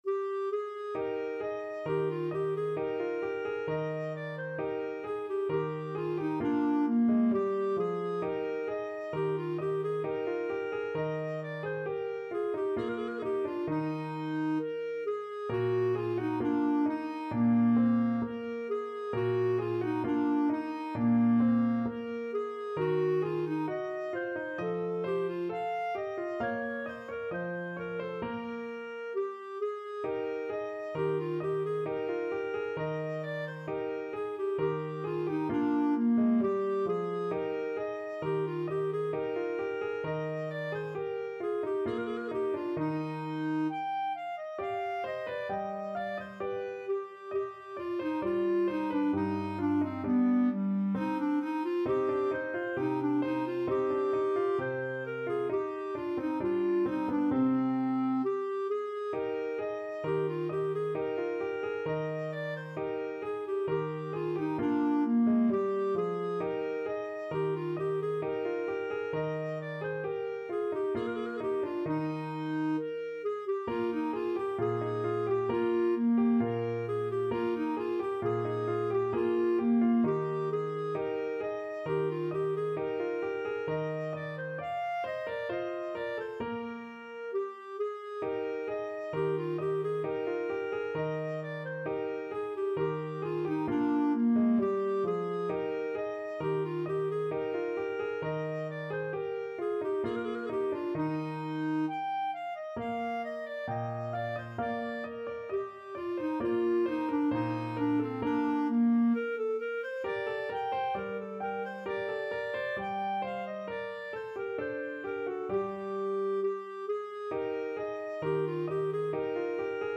Free Sheet music for Clarinet
Clarinet
~ = 100 Allegretto =c.66
2/2 (View more 2/2 Music)
Classical (View more Classical Clarinet Music)